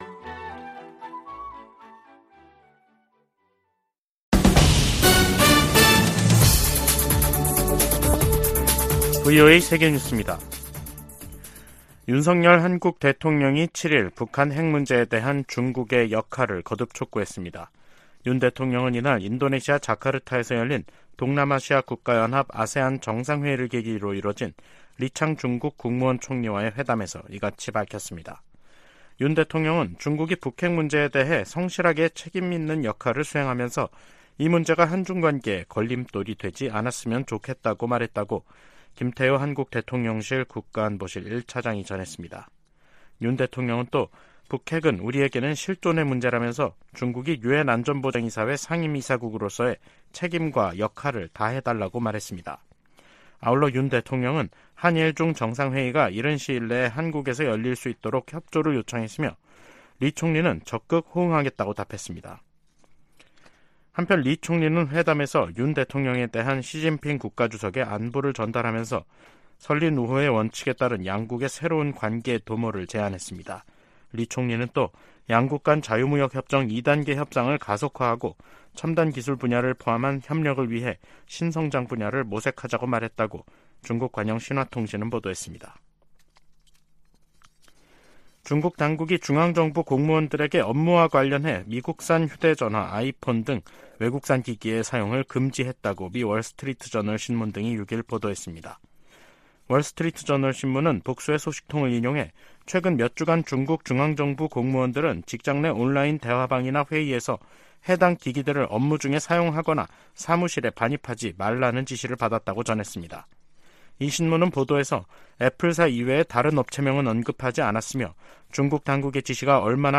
VOA 한국어 간판 뉴스 프로그램 '뉴스 투데이', 2023년 9월 7일 3부 방송입니다. 존 커비 백악관 국가안보회의(NSC) 전략소통조정관은 북한과 러시아 간 무기거래 협상을 면밀히 주시할 것이라고 경고했습니다. 동아시아정상회의(EAS)에 참석한 윤석열 한국 대통령은 대북 제재 준수에 유엔 안보리 상임이사국의 책임이 더 무겁다고 말했습니다. 유럽연합(EU)은 북러 정상회담 가능성에 관해 러시아의 절박한 처지를 보여줄 뿐이라고 지적했습니다.